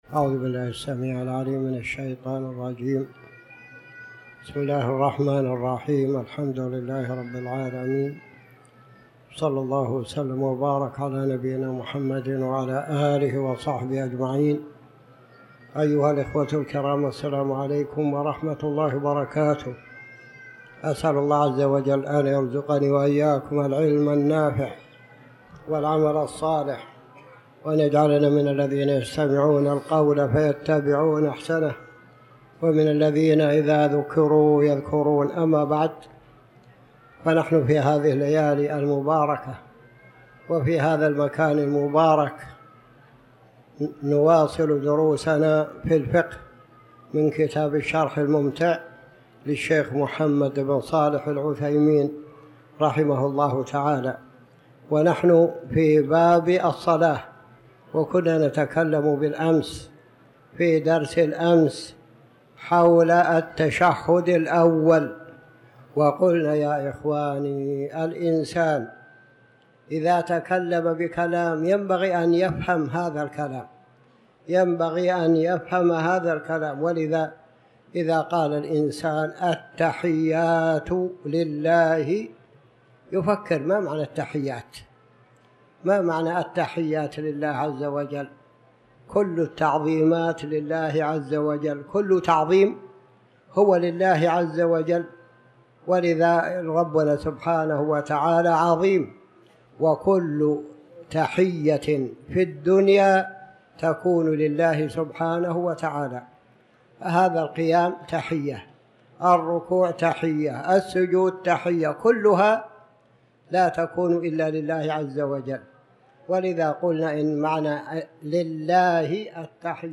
تاريخ النشر ١٣ ذو القعدة ١٤٤٠ هـ المكان: المسجد الحرام الشيخ